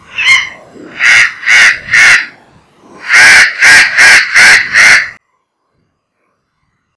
Posee una voz fuerte como otros de la familia pero es ronca y grave, bien característica.
VOZ Muy ruidoso. Disyllabic screaming scree-ah scree-ah. Loud gyeee gyeee gyeee cuando se alarma.
lorobarranquero.wav